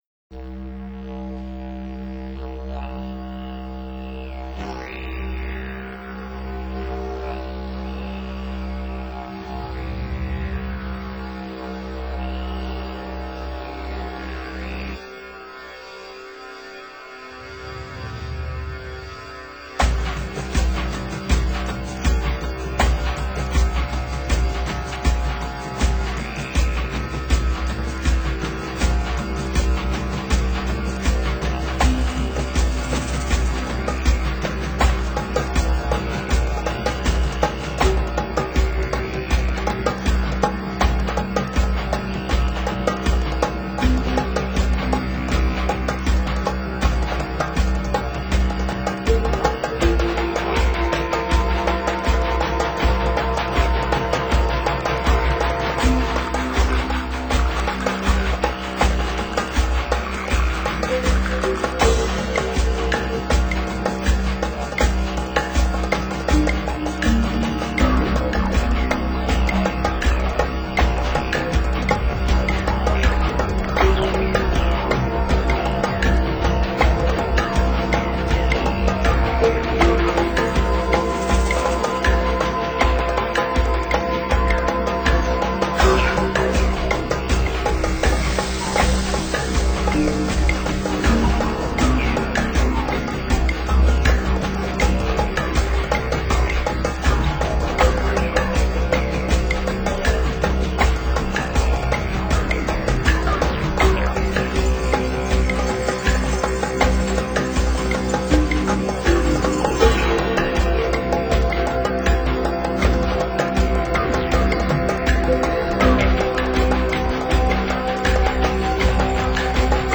专辑语言：纯音乐
强烈的节奏。快板依然混迹其中，但节奏上不如上一曲的有序。